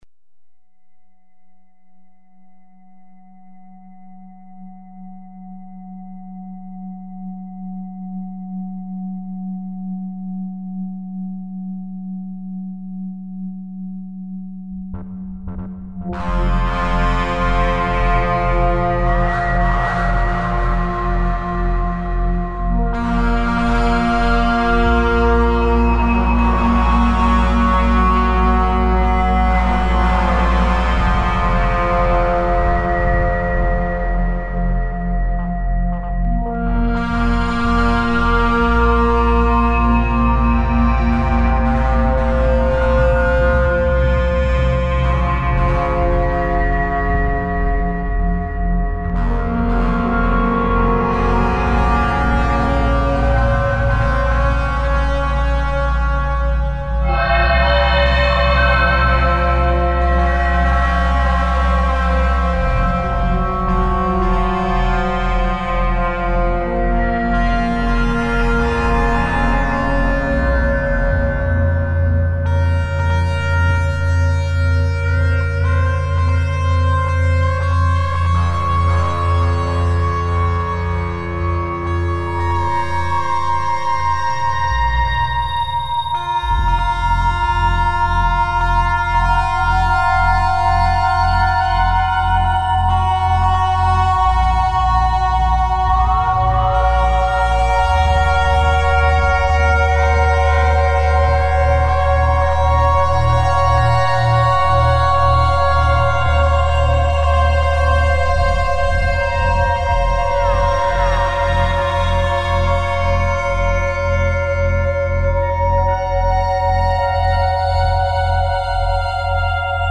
Studio Tracks